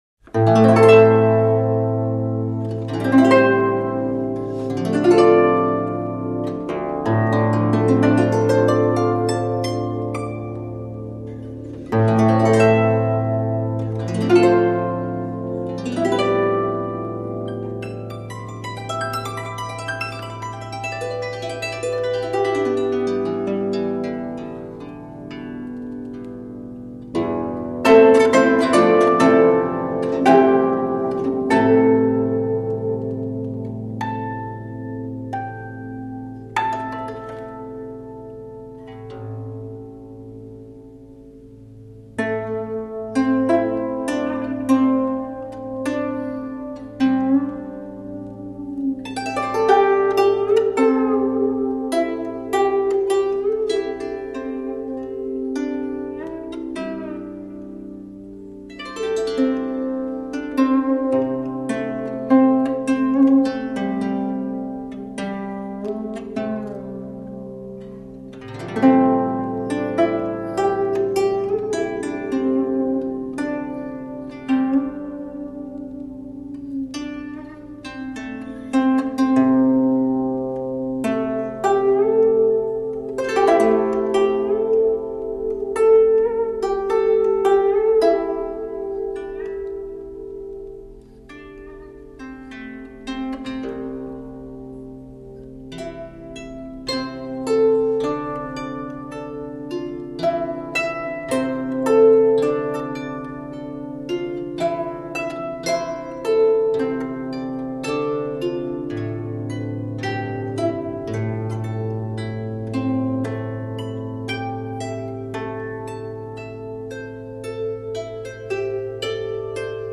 箜篌历史悠久、源远流长，音域宽广、音色柔美清澈，表现力强。
箜篌散音和泛音的交替出现，丰富了和声织体，更深刻地表现了两个好友互相道珍重，依依不舍的惜别之情。